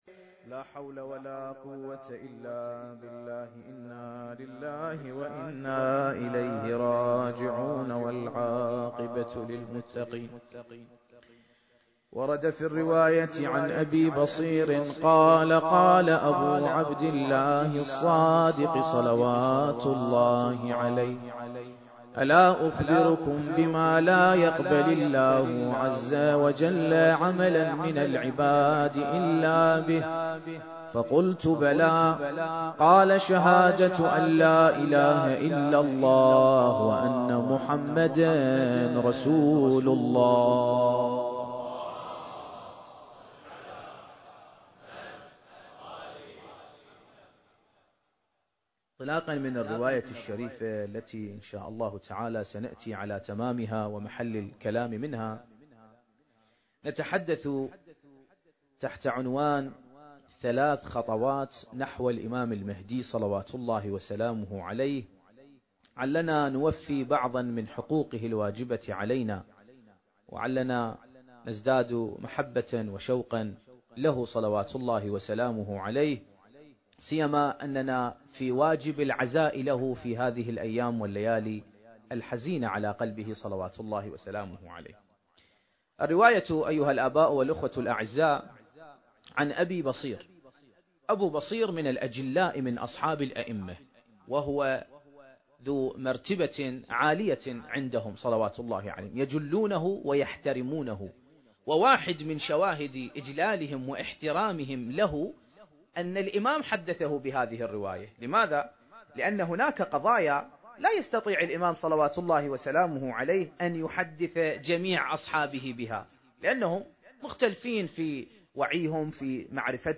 المكان: مسجد النبي محمد (صلّى الله عليه وآله وسلم)/ الشارقة التاريخ: 2024